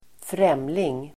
Uttal: [²fr'em:ling]